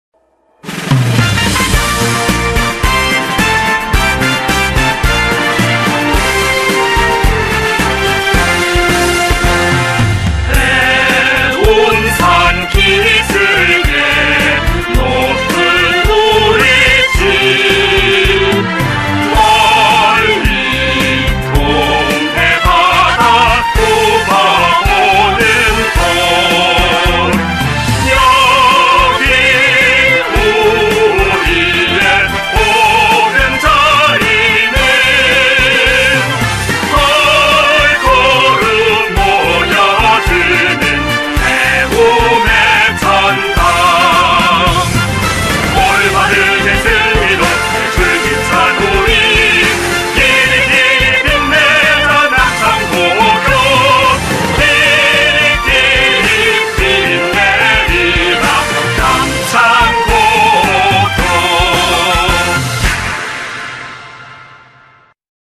남창고등학교의 교가 음원으로 홍두표 작사,김대현 작곡이다....